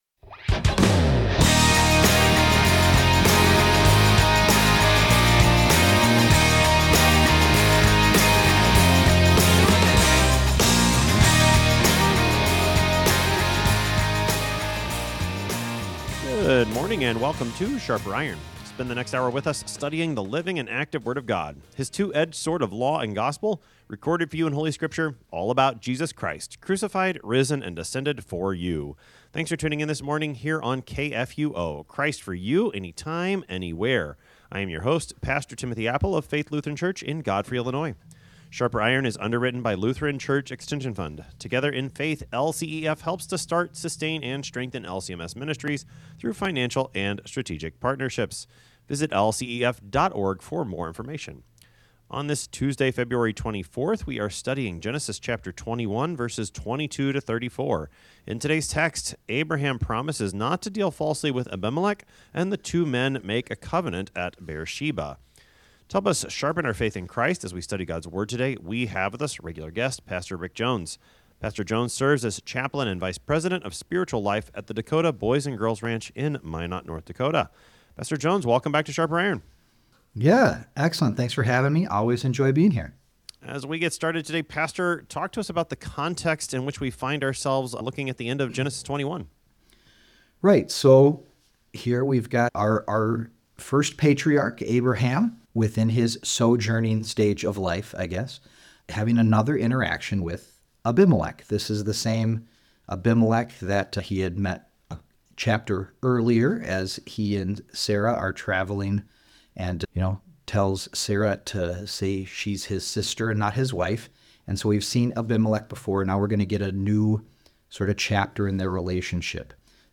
Two pastors engage with God’s Word to sharpen not only their own faith and knowledge, but the faith and knowledge of all who listen.